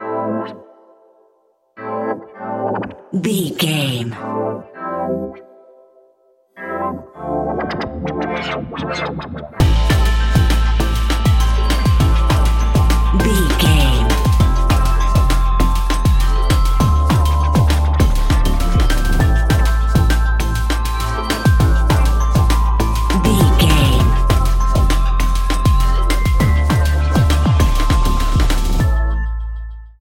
Fast paced
In-crescendo
Aeolian/Minor
driving
energetic
bass guitar
drums
synthesiser